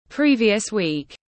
Tuần trước tiếng anh gọi là previous week, phiên âm tiếng anh đọc là /ˈpriːviəs wiːk/
Previous week /ˈpriːviəs wiːk/